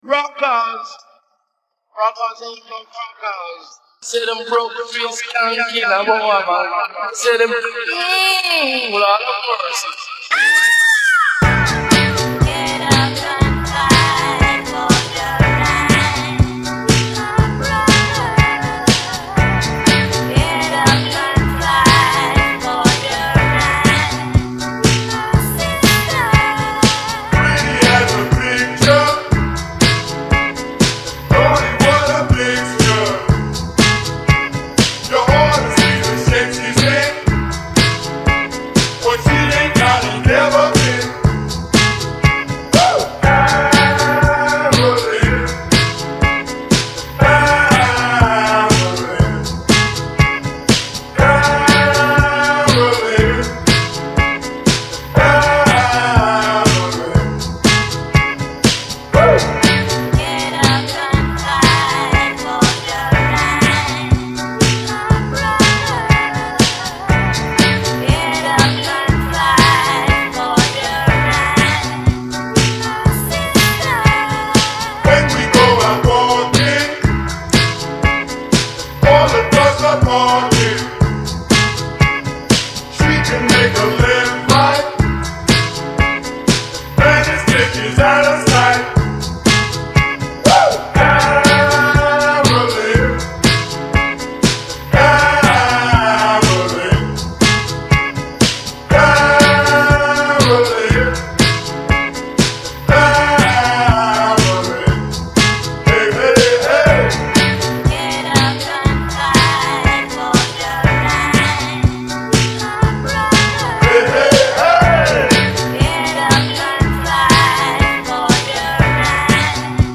This mashup